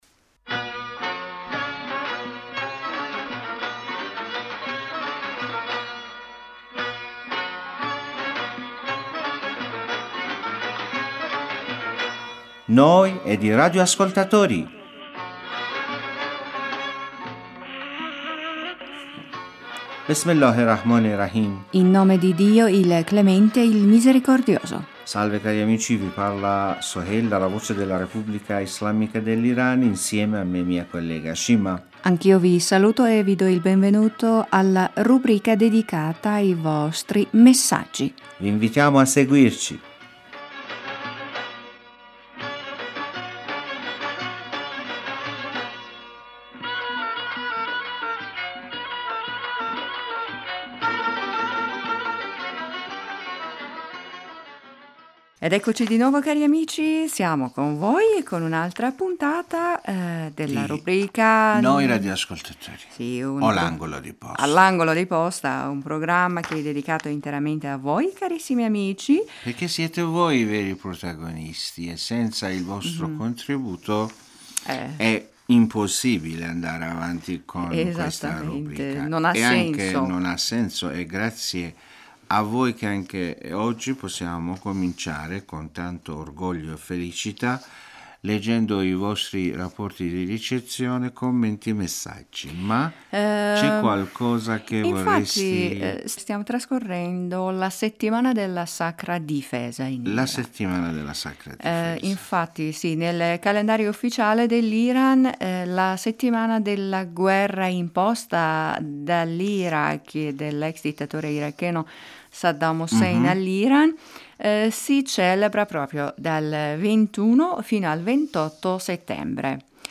Amici in questa puntata, in occasione della Settimana della Sacra difesa i nostri speaker oltre a leggere i vostri messaggi e commenti parlano anche di otto anni di Sacra difesa.